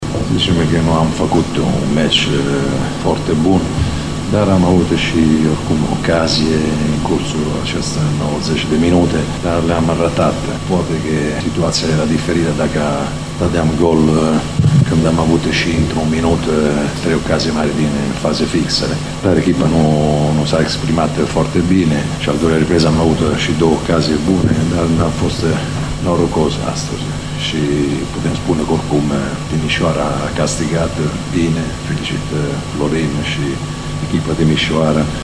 Victoria bănăţenilor a fost recunoscută şi de tehnicianul oaspeţilor, Cristiano Bergodi, care şi-a felicitat omologul de pe banca “alb-violeţilor”: